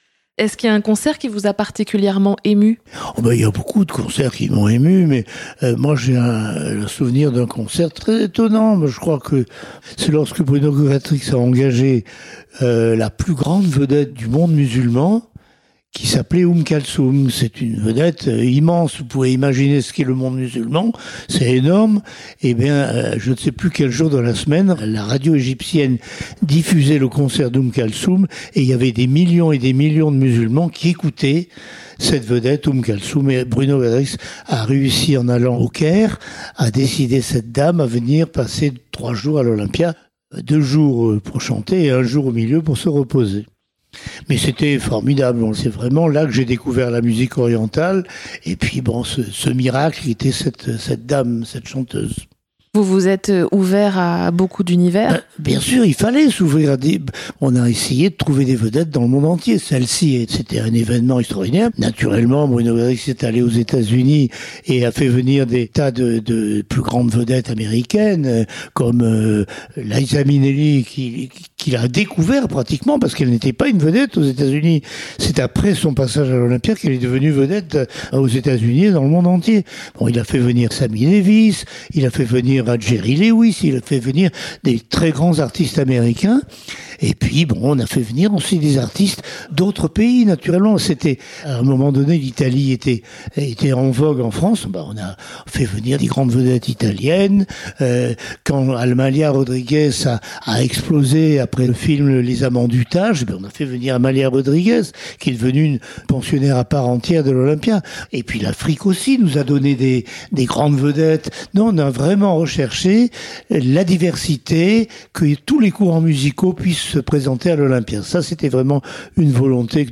Backstage le podcast : entretien